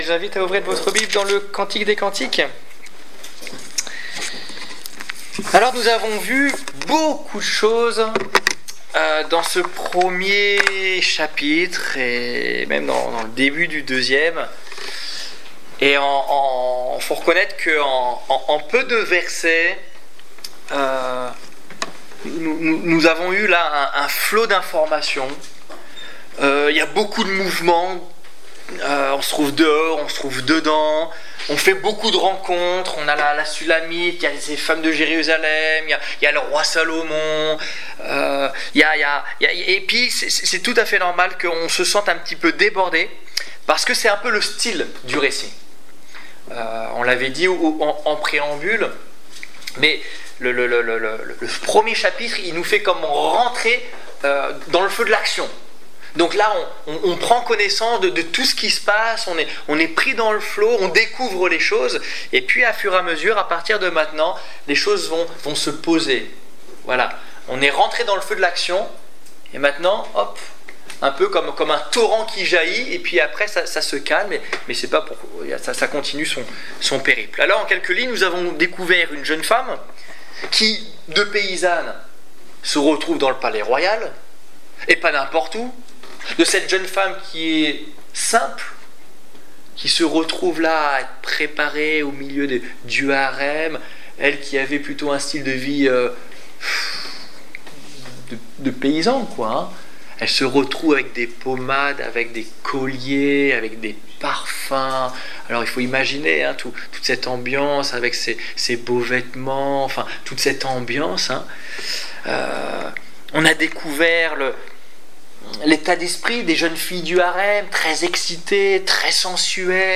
Étude biblique du 22 juillet 2015